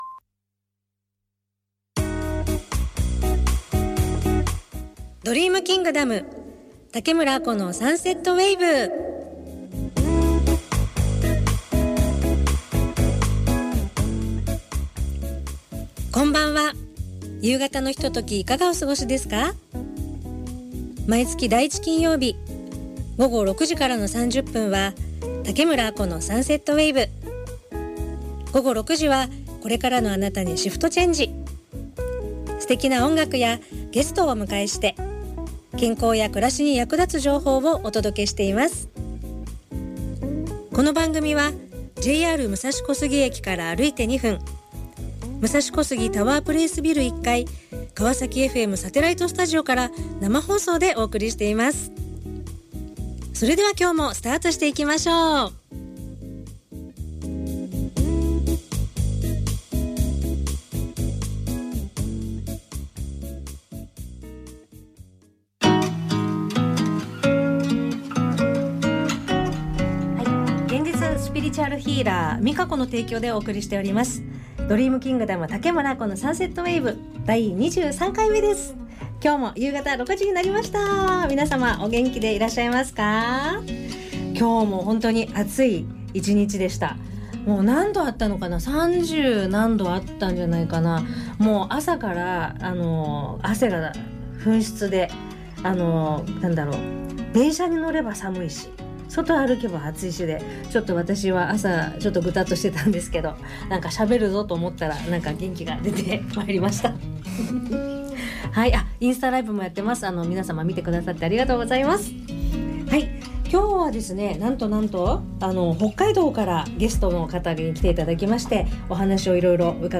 ＊かわさきFMサテライトスタジオから生放送